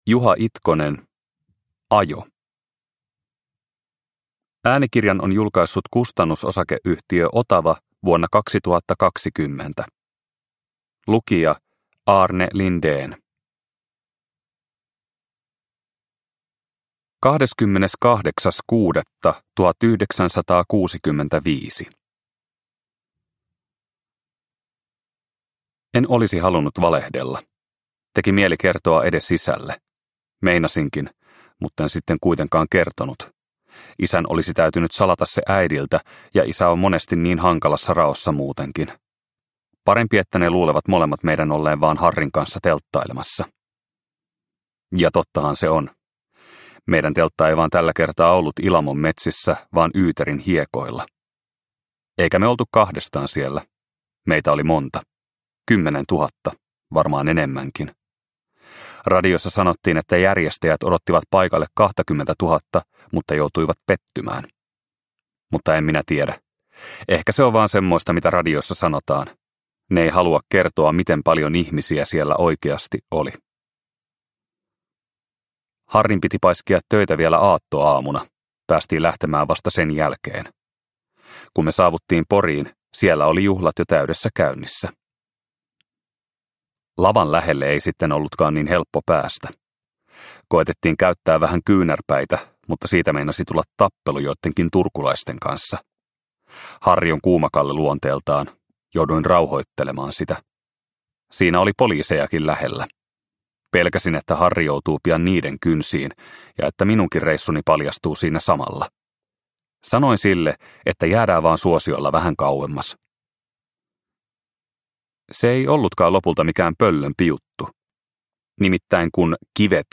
Ajo – Ljudbok – Laddas ner